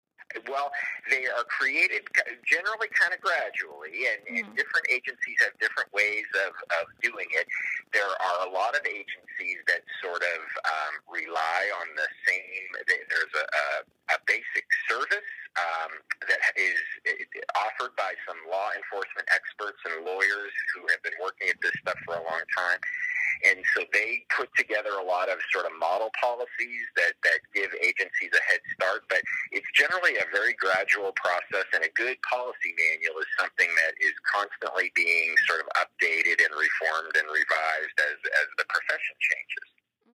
Through interviews